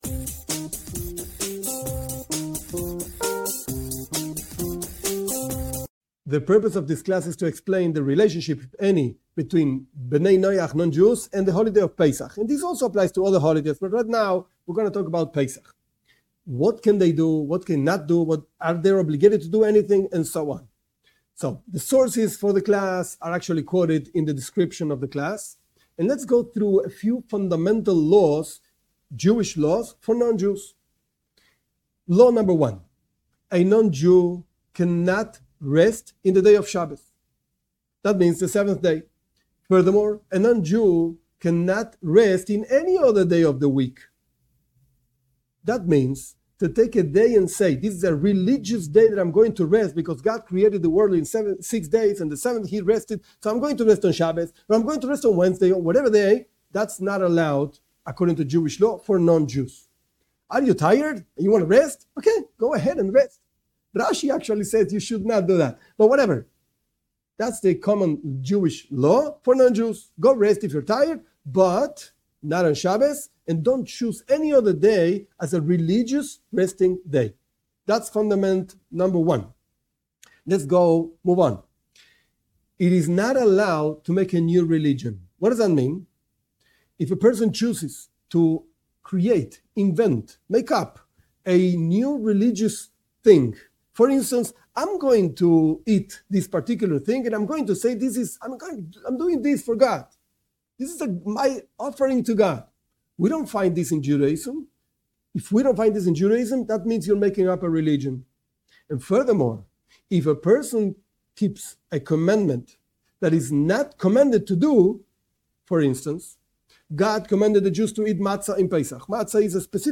This class explains what Judaism expects of non Jews regarding the holiday of Pesach: what do they have to do? What can they do and what can they not do?